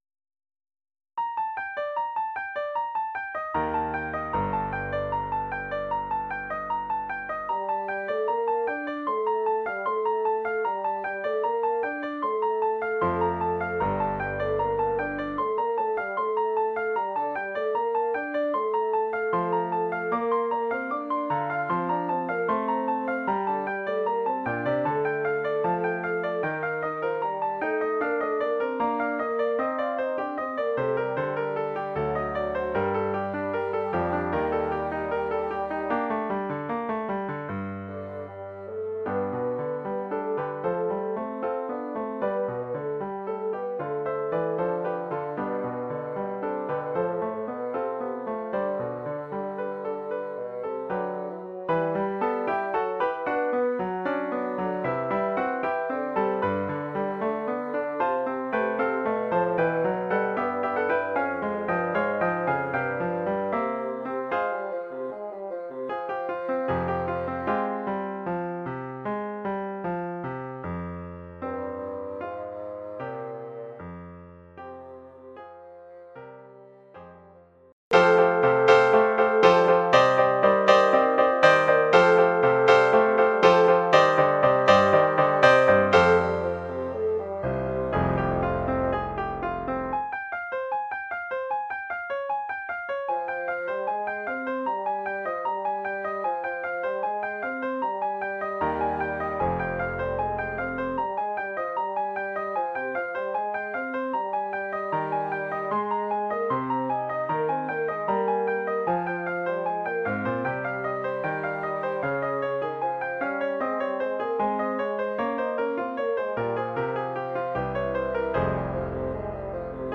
Formule instrumentale : Basson et piano
Pièce originale pour basson et piano.